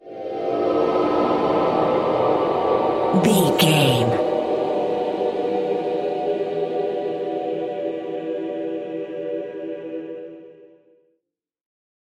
Sound Effects
Atonal
tension
ominous
eerie
synth
pads